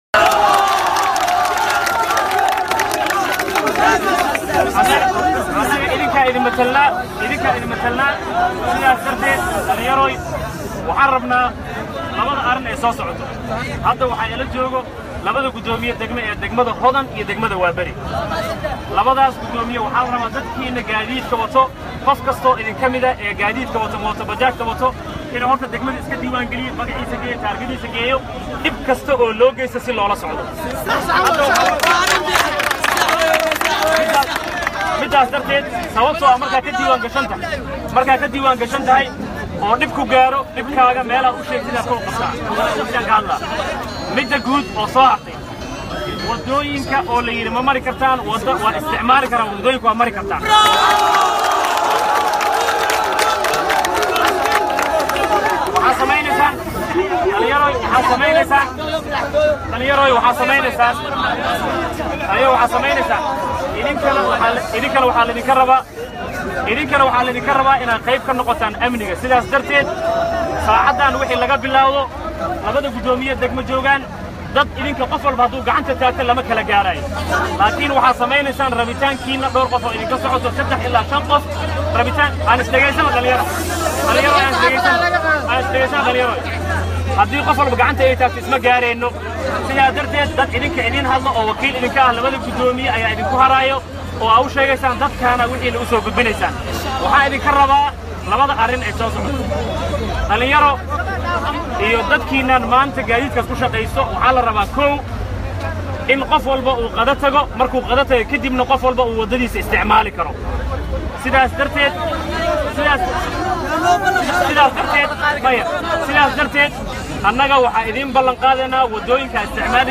Dhageyso codka: Gudoomiyaha Gobolka Banaadir oo la hadlay Banaanbaxayaal caraysan Balan qaadna u sameeyay (Sawirro) | Goobsan Media Inc
banaan-baxayaal-bajaajley-.mp3